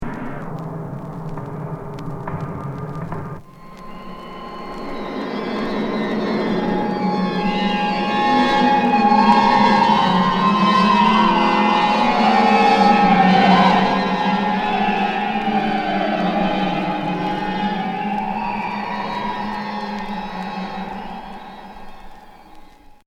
Expérimental Unique 45t